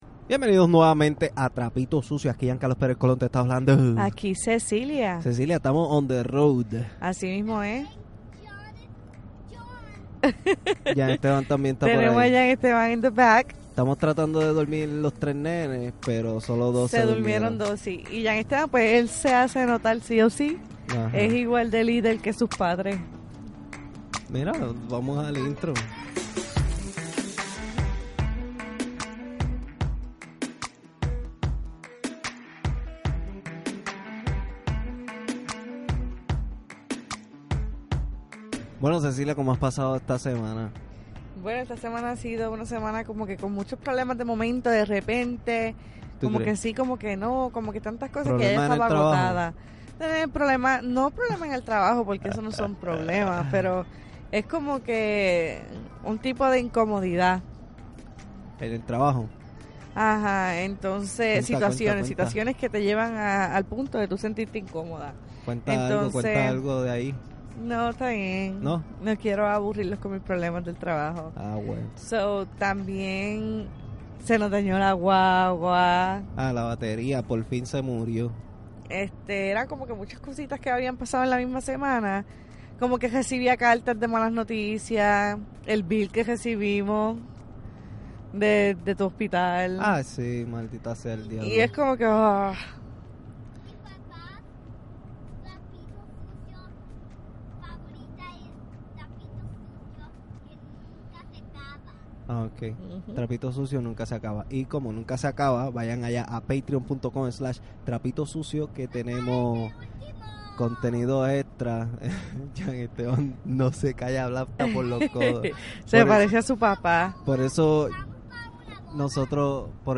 Que mejor que grabar durante un road trip el camino se vuelve corto y los minutos pasan volando en realidad hablamos de todo un poquito.